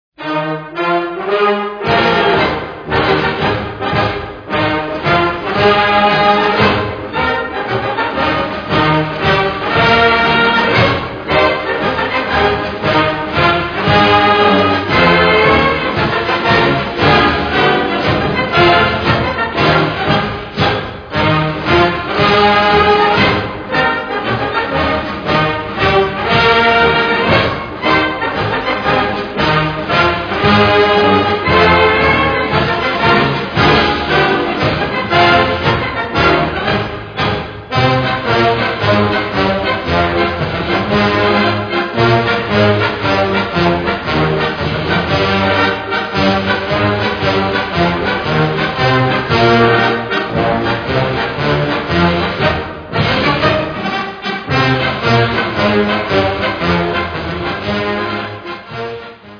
Gattung: Defilier-Marsch im 6/8-Takt
Besetzung: Blasorchester